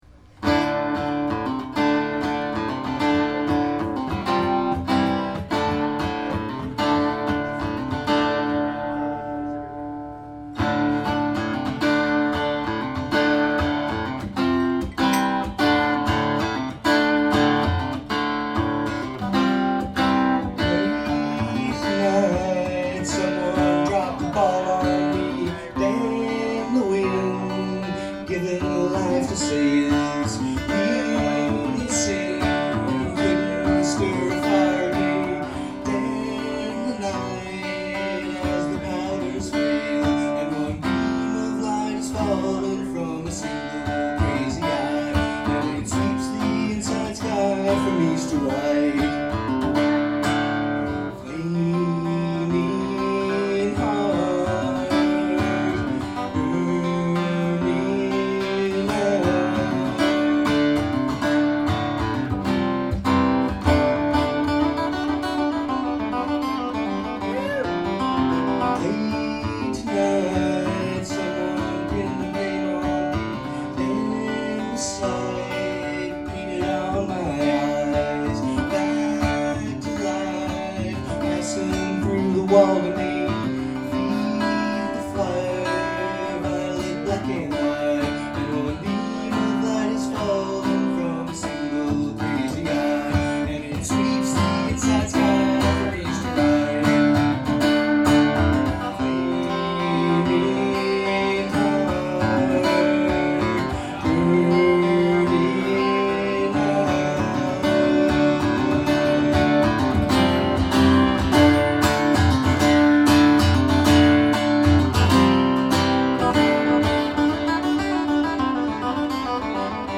The quality is outstanding.